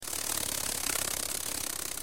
本音频素材为：昆虫拍动翅膀飞起声音音效，格式为mp3，动物音效风格，本站所有资源均为可费下载-音素阁